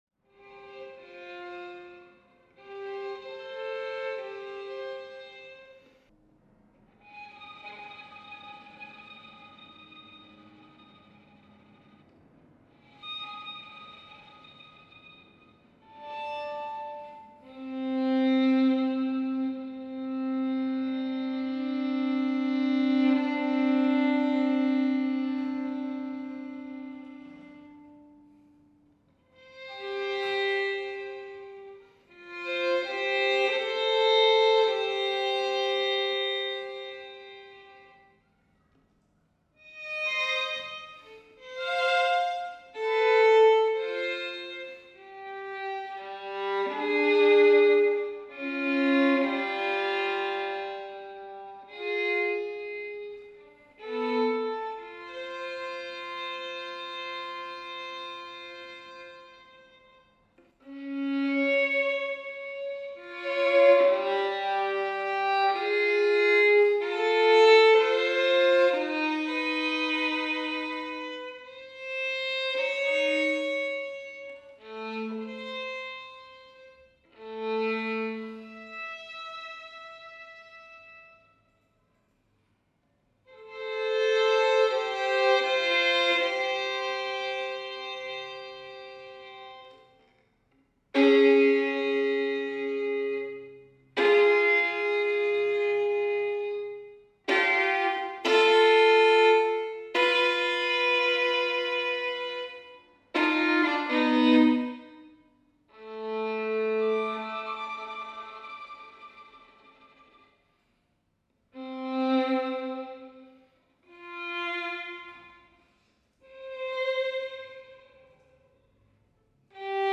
Outtakes from recording session-20 3 17